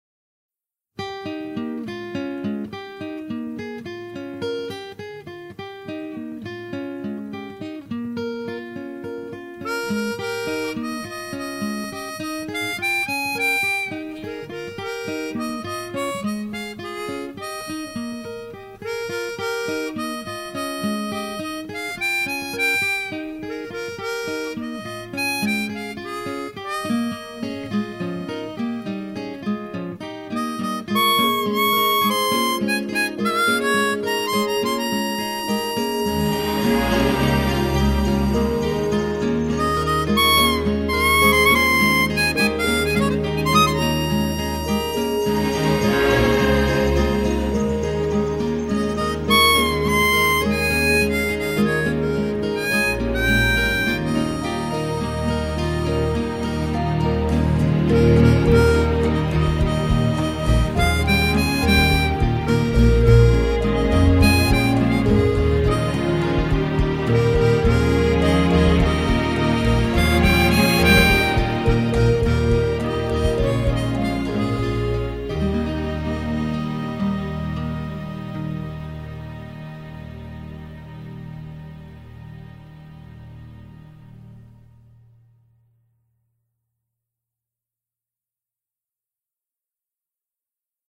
Le résultat est plus riche que prévu.